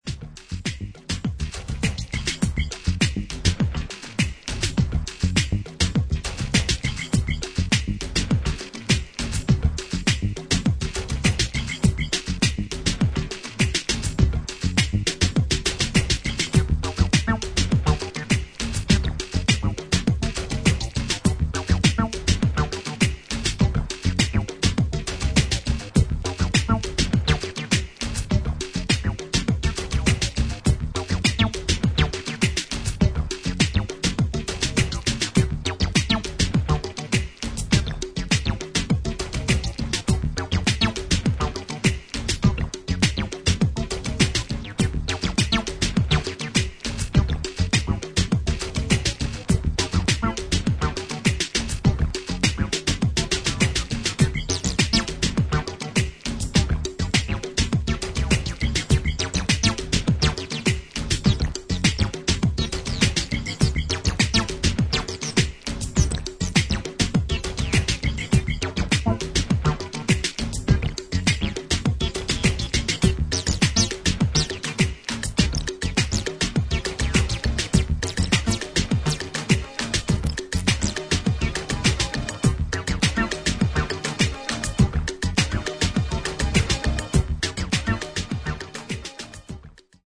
スローで抜けの良いキックに腰にくるベース、アシッド・シンセの絡みが絶妙なA1